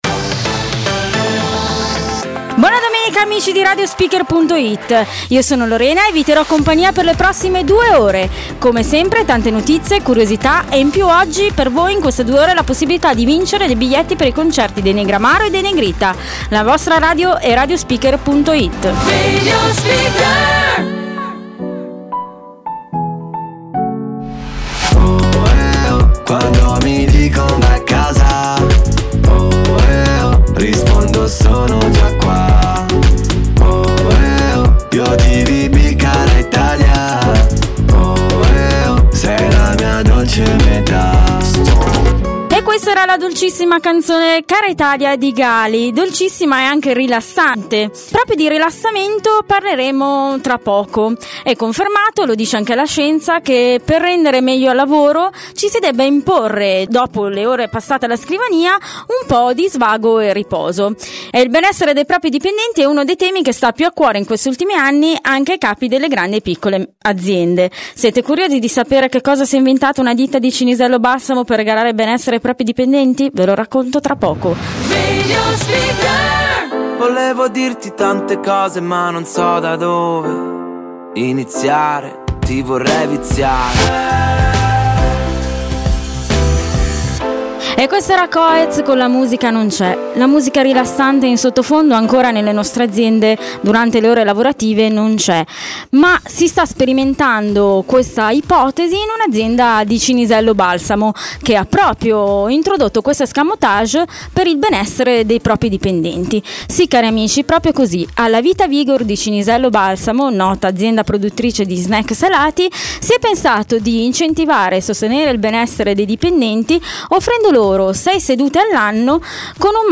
La mia prima demo: